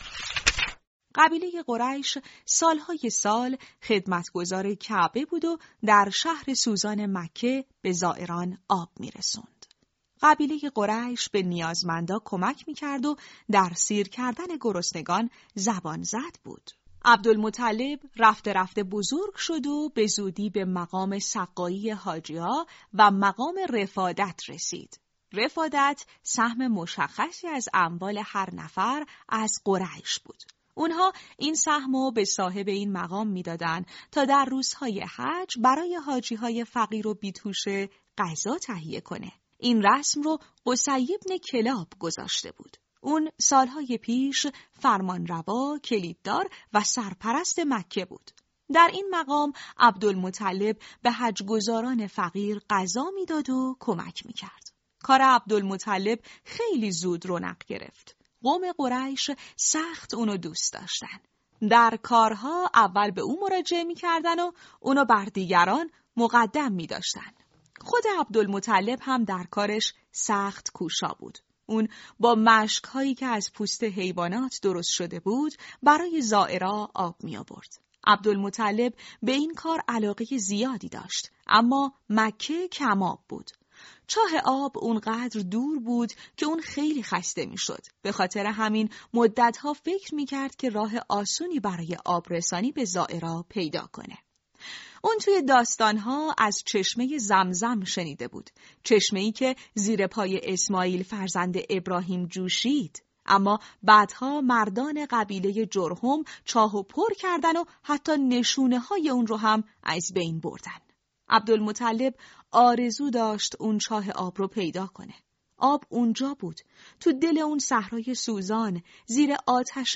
دانلود صوت بفرمایید قصه کتاب صوتی «۳۶۶ روز با پیامبر عزیزمان» فصل سوم راوی
# کتاب صوتی # قصه # قصه کودک